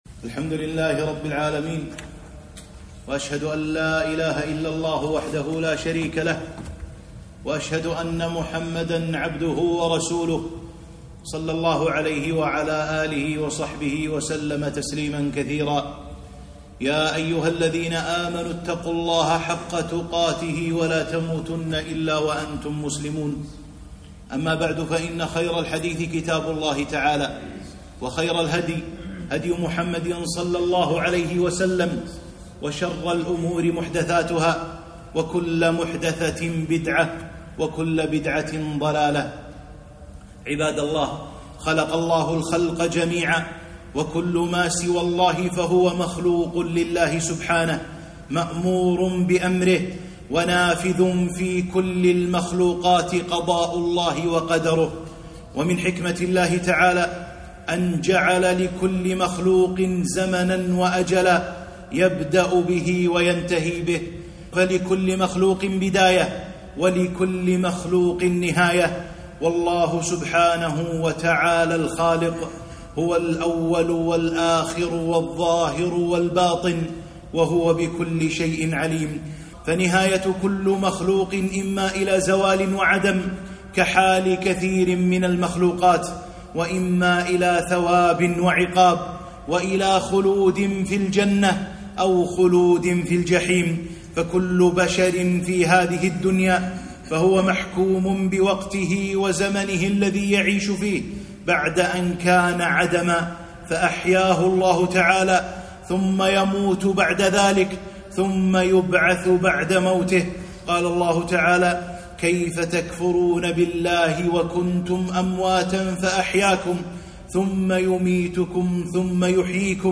خطبة - الإيمان بالغيب